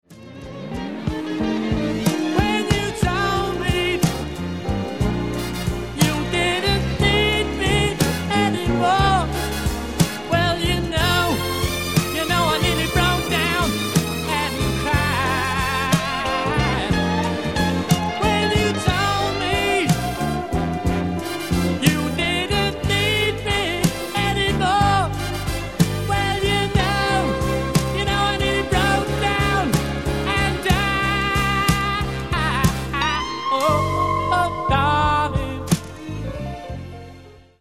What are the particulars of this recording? RECORDED AND MIXED AT CHEROKEE STUDIOS, LOS ANGELES